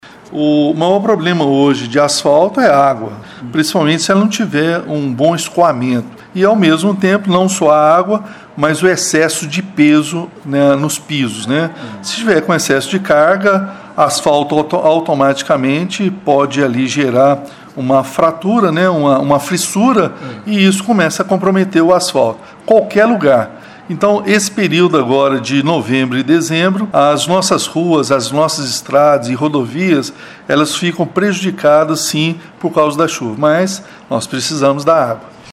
Com a chegada das chuvas Elias acredita que as obras ligadas à pavimentação deverão ter o ritmo reduzido. Também lamenta o aumento de problemas que este período traz para a malha viária, com o surgimento de buracos.